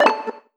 notification-pop-in.wav